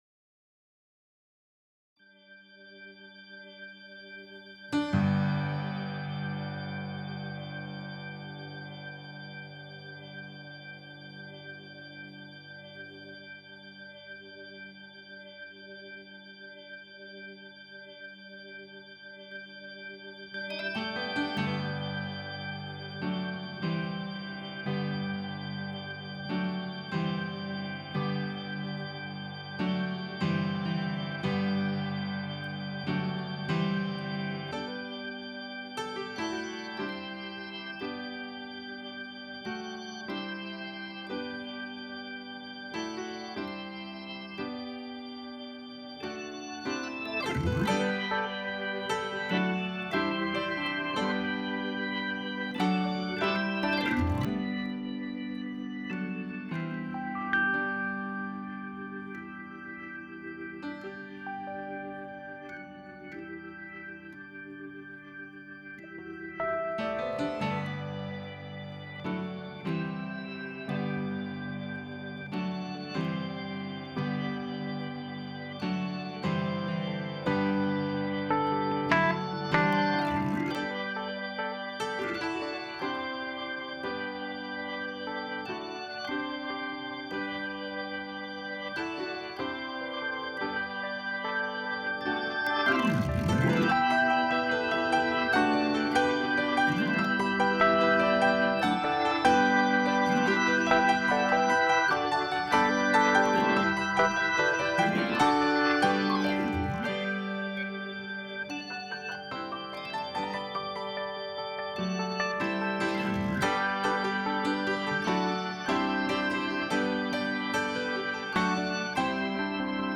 Bergmann Upright Acoustic Piano (Samples above)
• Universal Audio Rhodes Suitcase with Various Speaker Options and FX Pedals (Samples above)
• Universal Audio: Hammond Organ & Leslie Rotary Speaker (Requires organ midi controller with Leslie Speed Pedal) (Samples above)